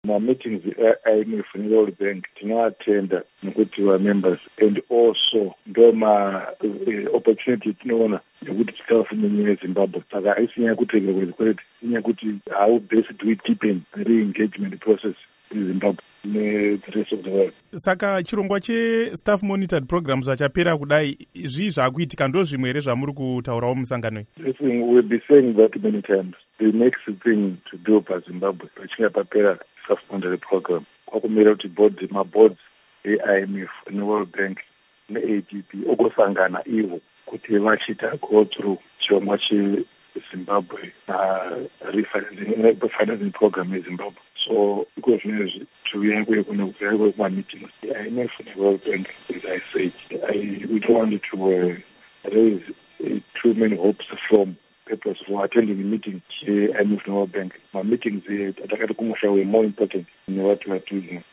Hurukuro naDr John Mangudya